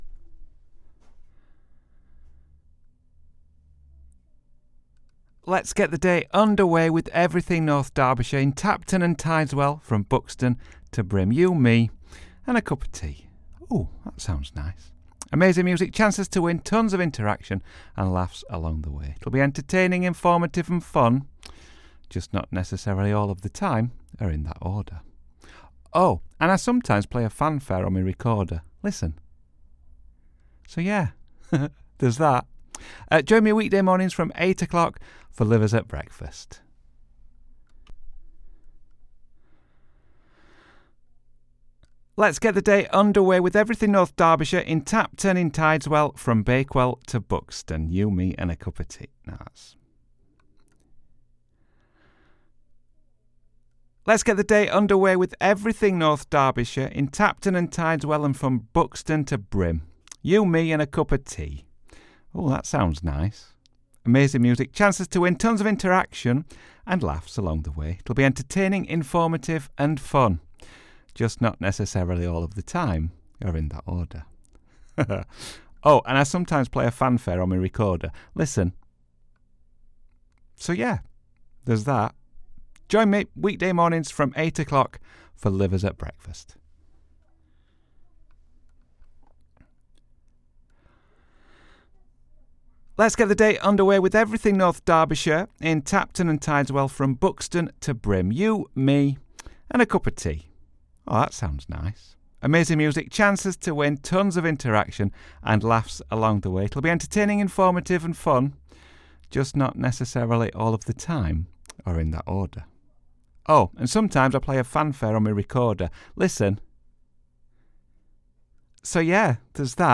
Here’s the promo for the show: